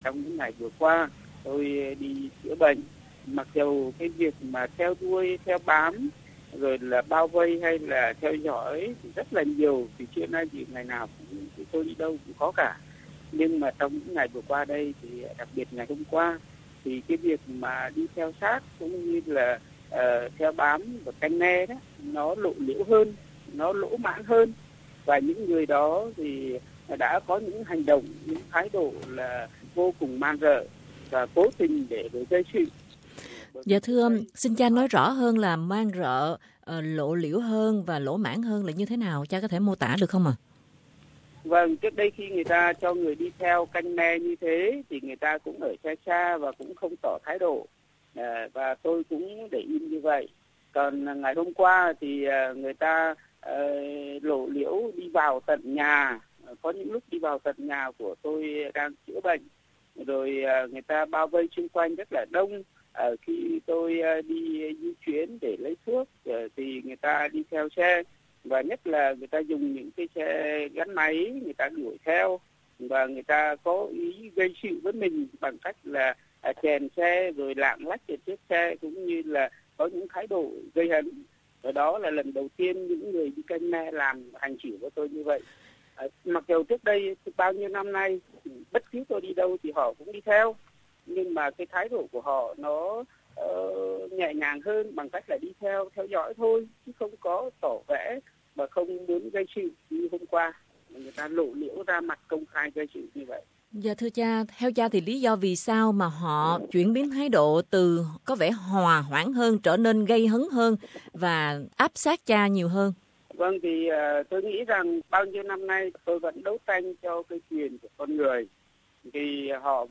Và trong cuộc trả lời phỏng vấn với SBS hôm nay